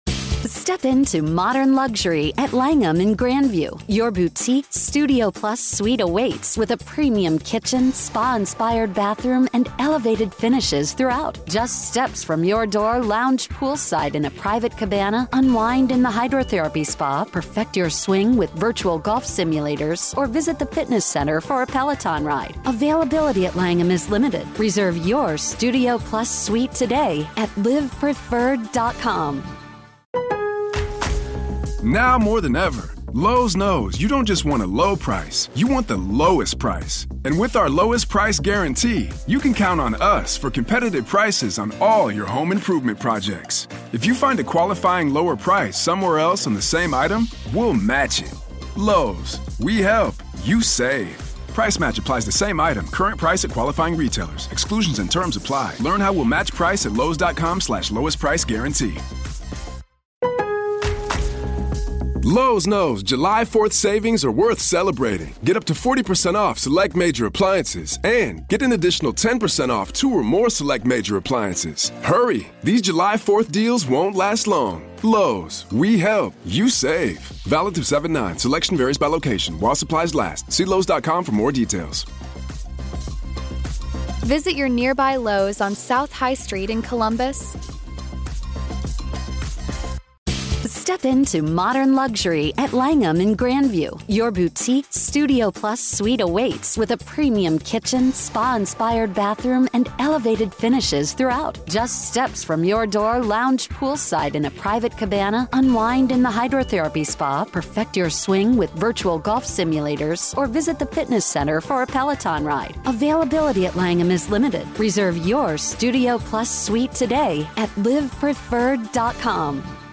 In this in-depth interview